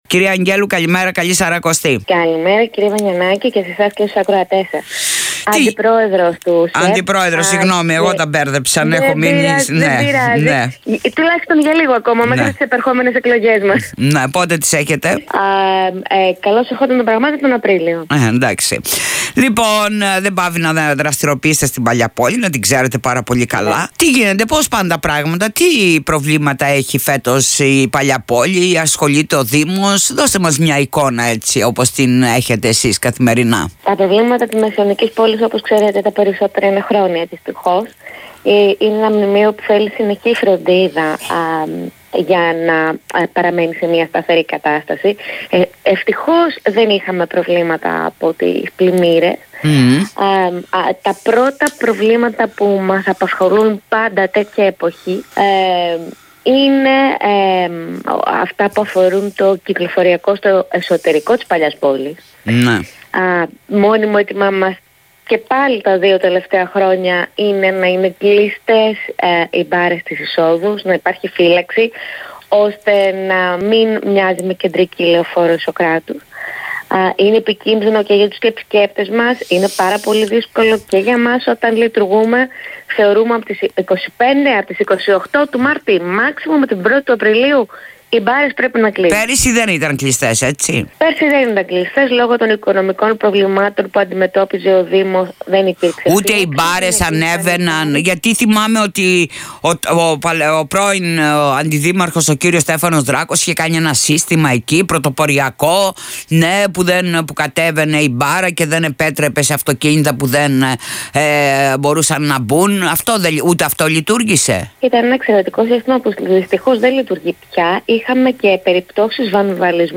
για τονTOP FM